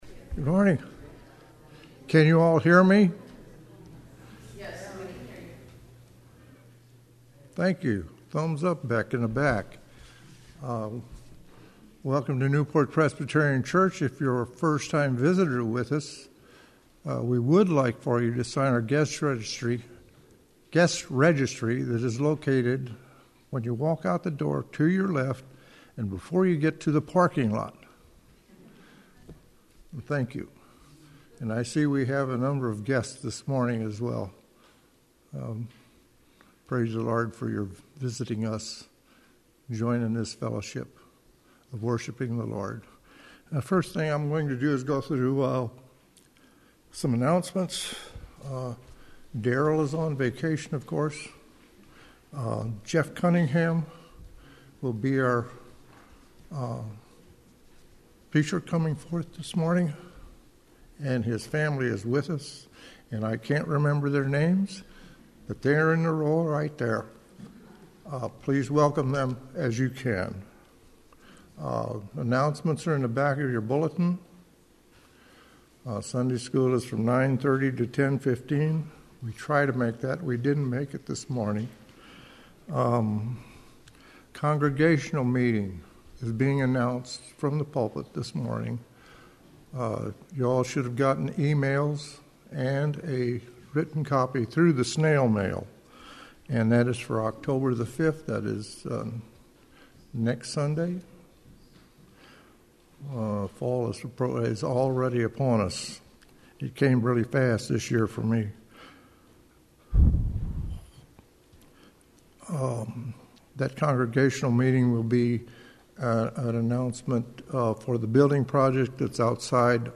Worship Service, New Port Presbyterian Church, Colossians 3:1 - 11